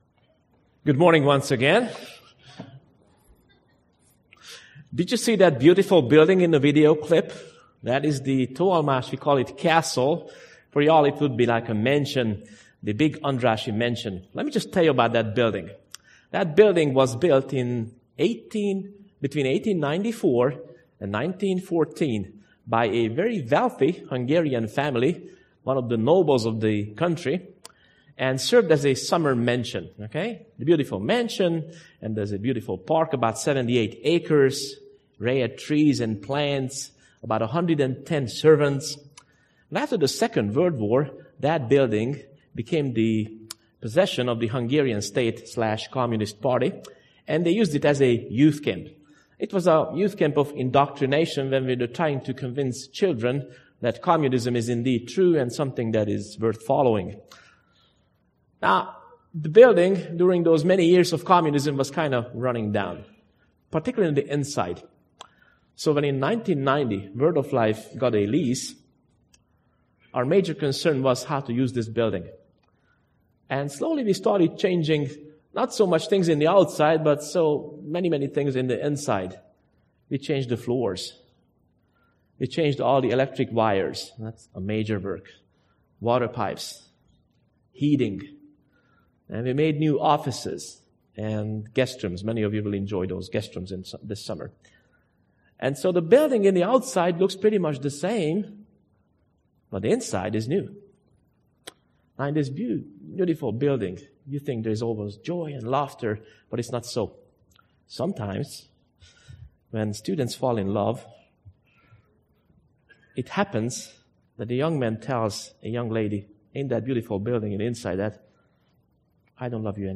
Sermon7.1.18b.mp3